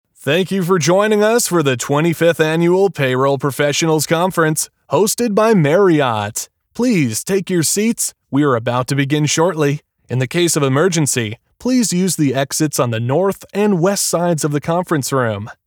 Live Announcer
Voice Of God Vog Announcer
Words that describe my voice are young voice over, american voice over, male voice over.